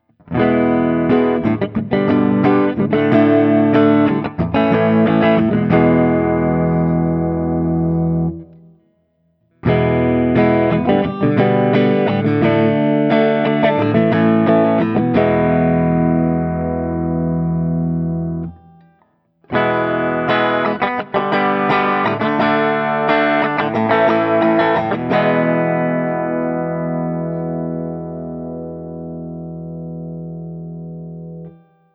Open Chords #2
As usual, for these recordings I used my normal Axe-FX II XL+ setup through the QSC K12 speaker recorded direct into my Macbook Pro using Audacity. I recorded using the ODS100 Clean patch, as well as the JCM-800 and one through a setting called Citrus which is a high-gain Orange amp simulation.
For each recording I cycle through the neck pickup, both pickups, and finally the bridge pickup. All knobs on the guitar are on 10 at all times.